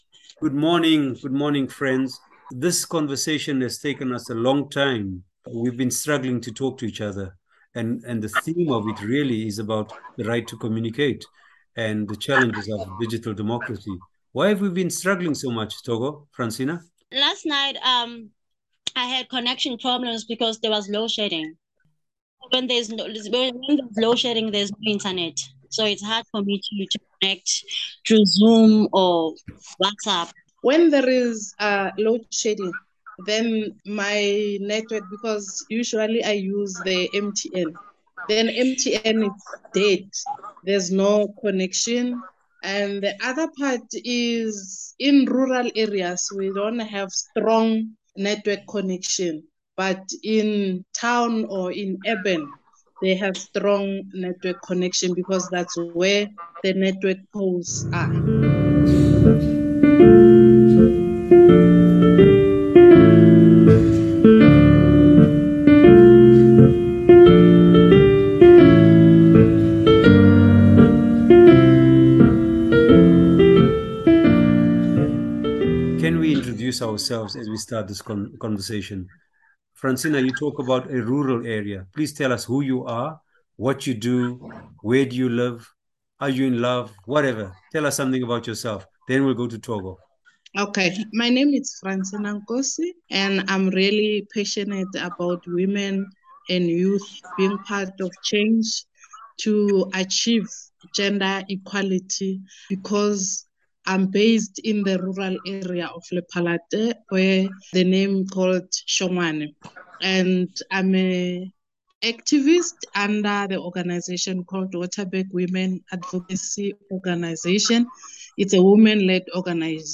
wherearethedigitalhubs-a-conversation.mp3